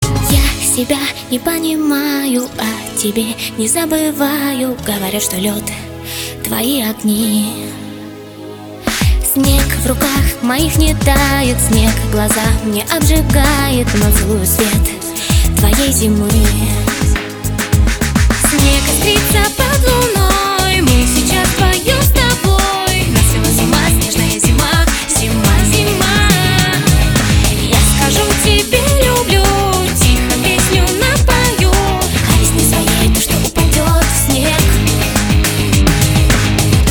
женский вокал
спокойные